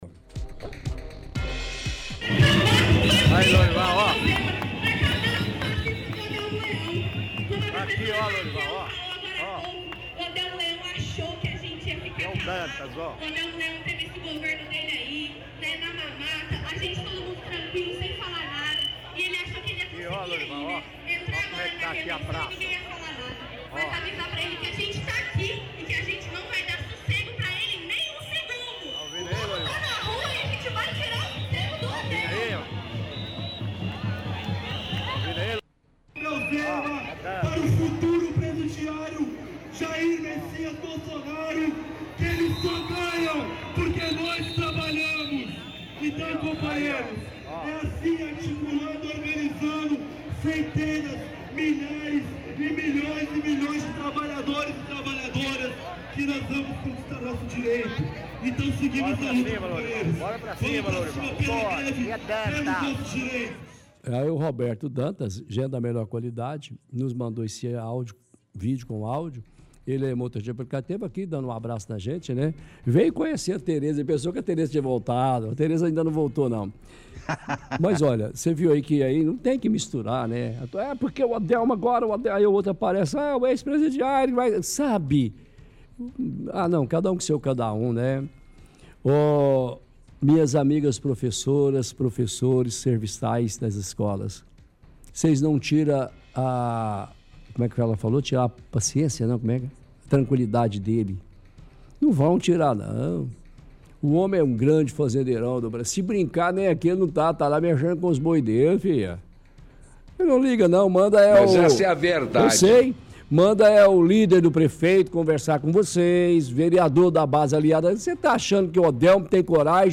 – Ouvinte transmissão de áudio de vídeo da manifestação de servidores municipais na praça.